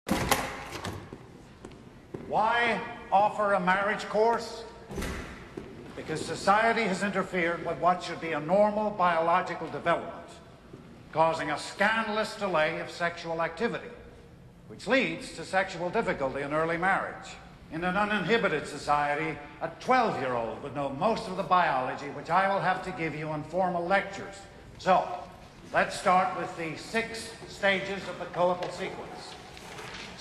Biologist Alfred Kinsey (Liam Neeson) gives his first lecture on human sexuality at Indiana University around 1948. The teacher starts the lecture while entering the room.